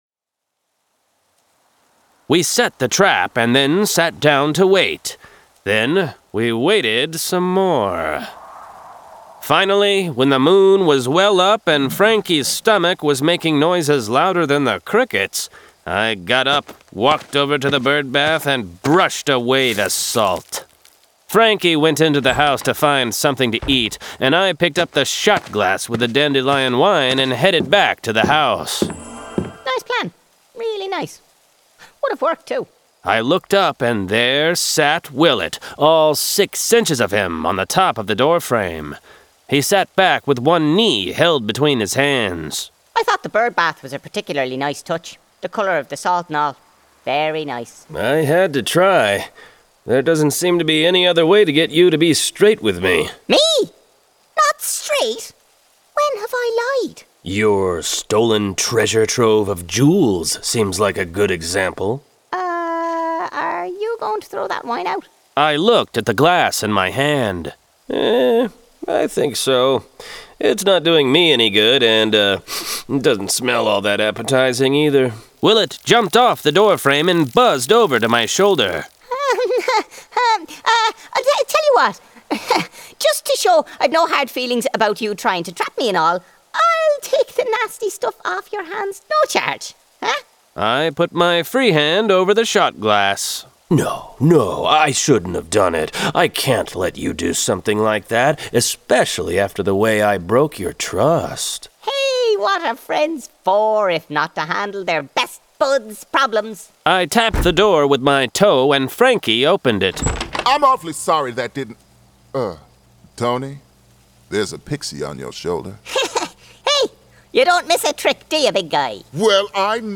Full Cast. Cinematic Music. Sound Effects.
[Dramatized Adaptation]
Genre: Urban Fantasy